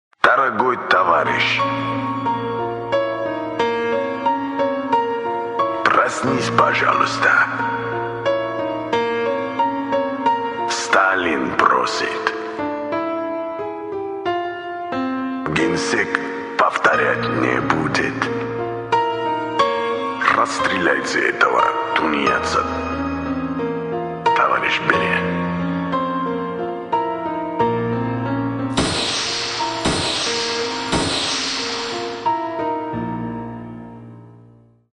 довольно мрачно получилось. Товарищь сталин призывает проснуться, музыка в тему :)
Stalin_Lirika.mp3